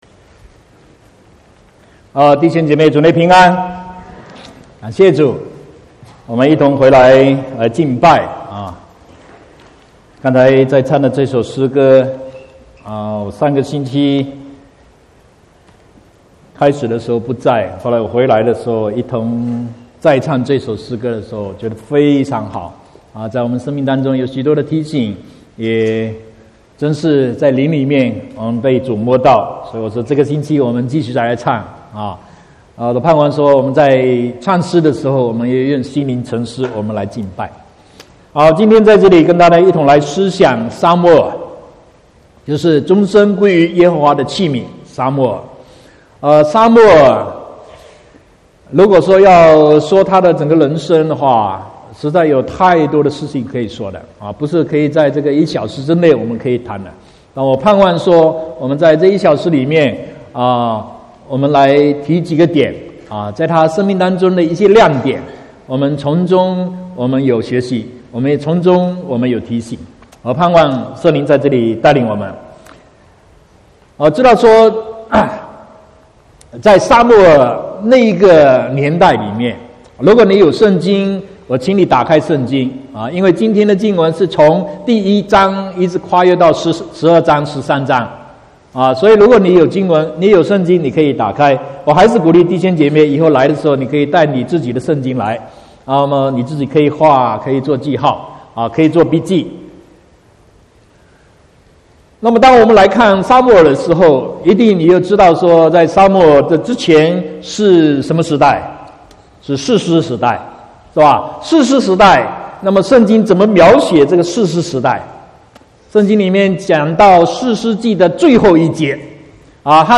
22/7/2018 國語堂講道